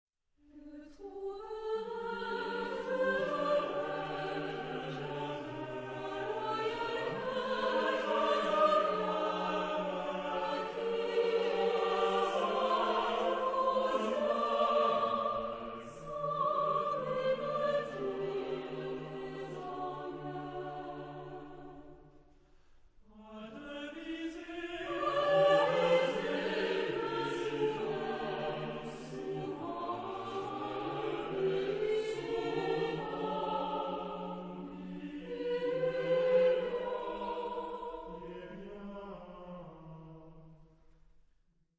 Chansons et madrigaux, chœur à trois ou quatre voix mixtes et piano ad libitum